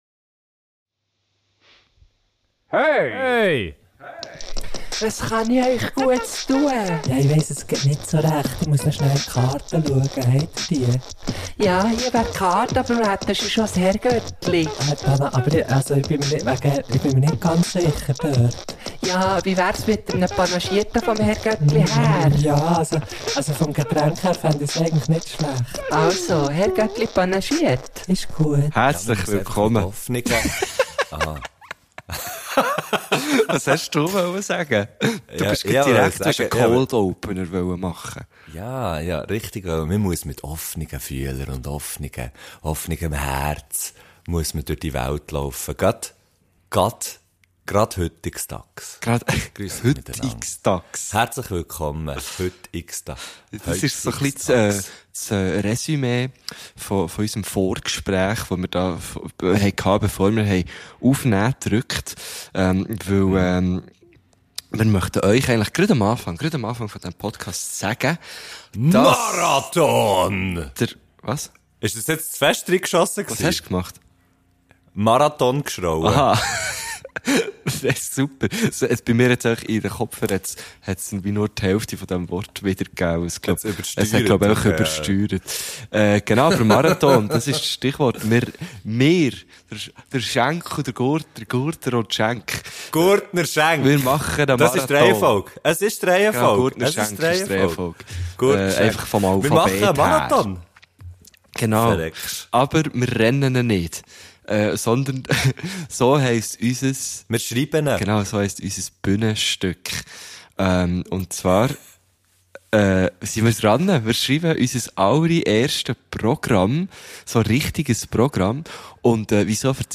Die Folge startet mit einer (für uns) riiiesigen Ankündigung: Unser neues Bühnenprogramm ist in der Mache und die ersten Tourtermine stehen. Wir freuen uns riesig darauf und schreien es (teilweise) auch ins Mikrofon.